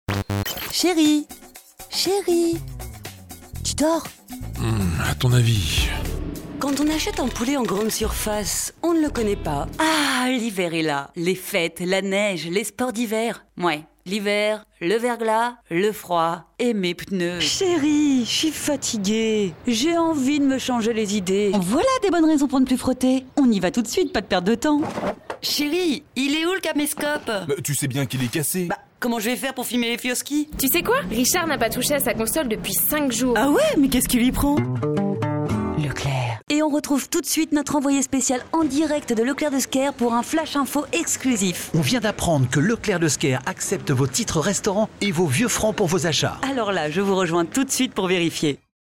Natürlich, Zuverlässig, Erwachsene, Freundlich
Persönlichkeiten
She works from her personal studio so that your projects are recorded in the best possible quality.